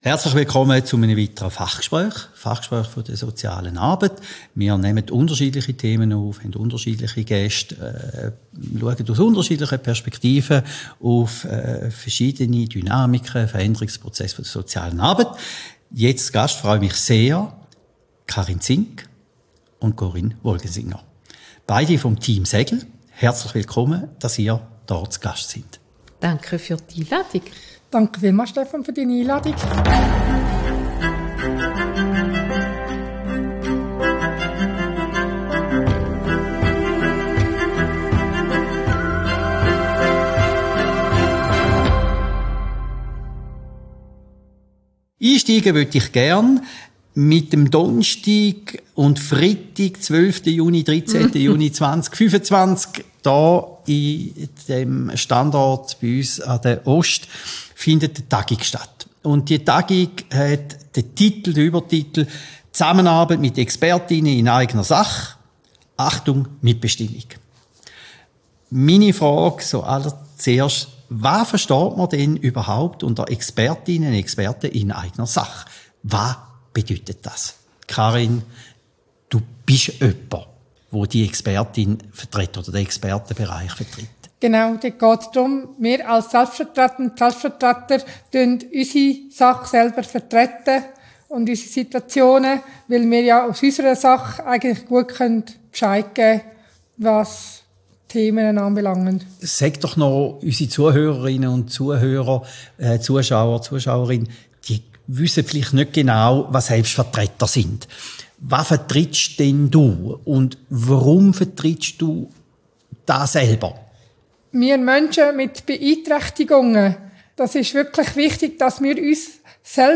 Fachgespräch